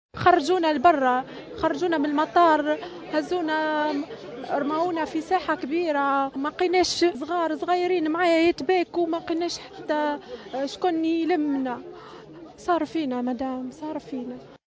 تونسية كانت بمطار أتاتورك لحظة الهجوم تروي التفاصيل